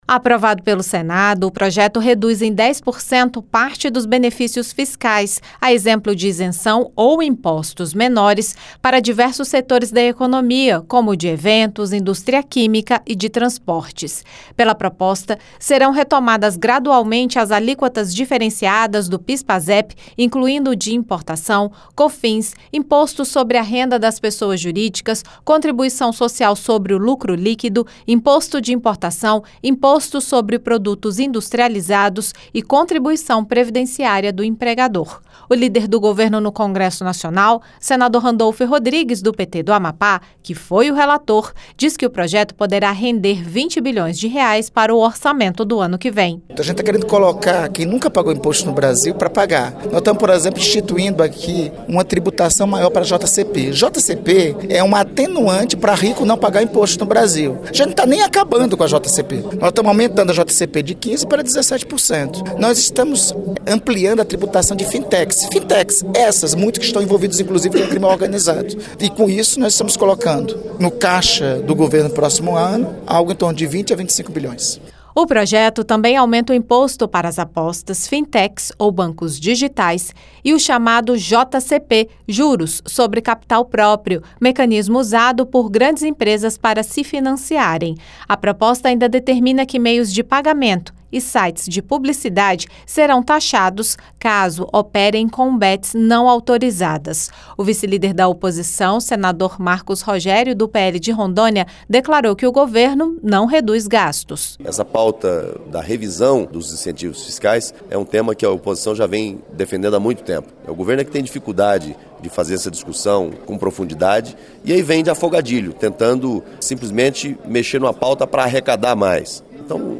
O projeto também aumentou a taxação para bets, fintechs e juros sobre capital próprio. O relator, senador Randolfe Rodrigues (PT-AP), declarou que o projeto vai render R$ 20 bilhões em arrecadação para o Orçamento do ano que vem.